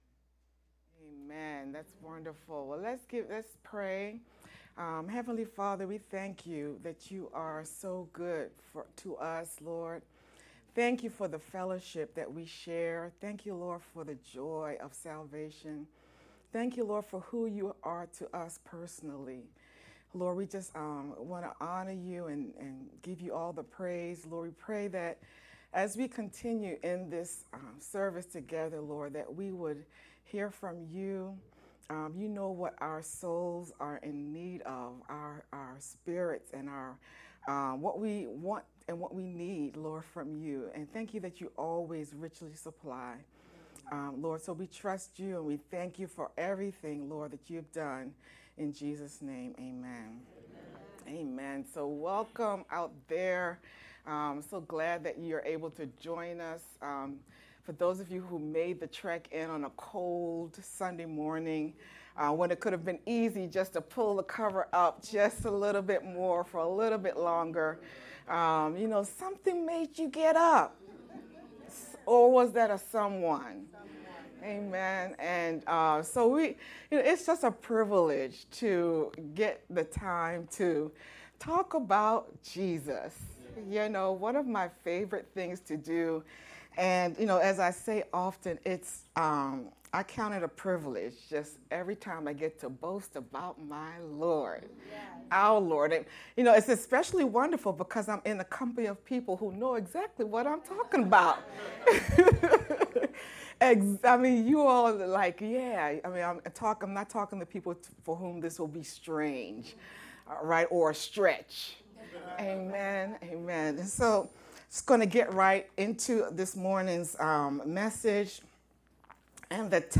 VBCC-sermon-only-11-31_Converted.mp3